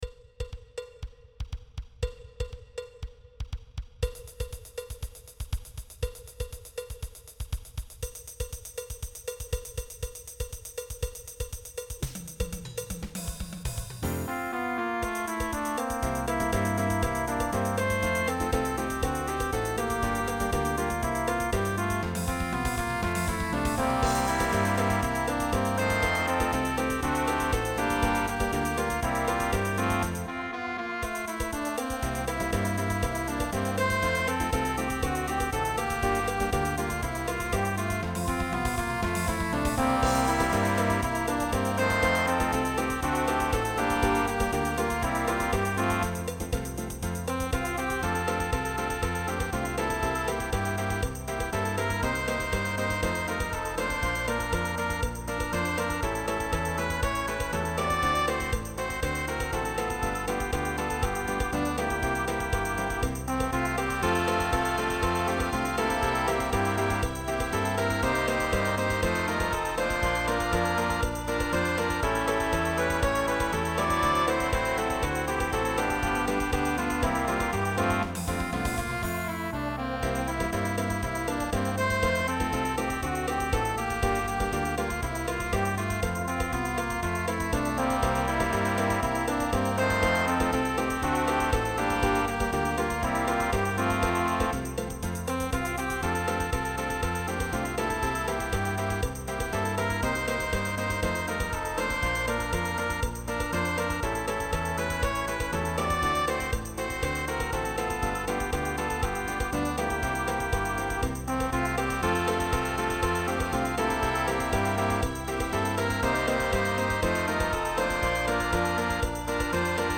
ORCHESTRA DIDATTICA